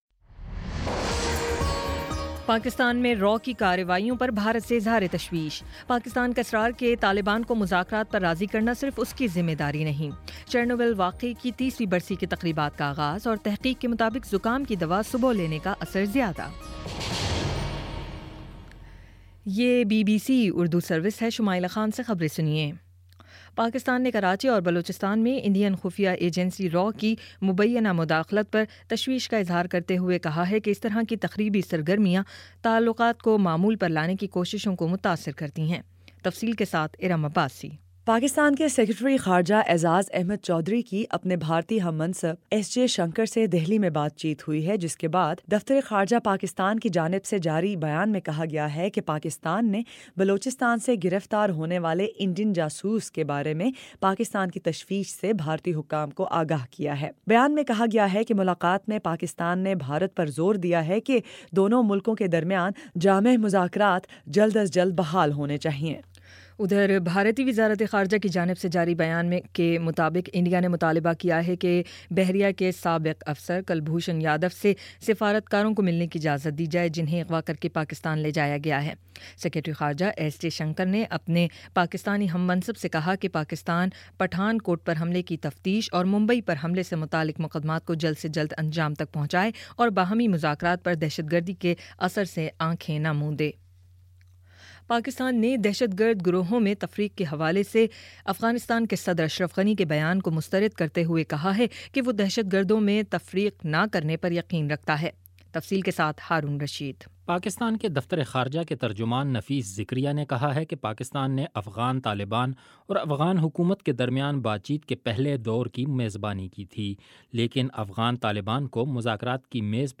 اپریل 26 : شام پانچ بجے کا نیوز بُلیٹن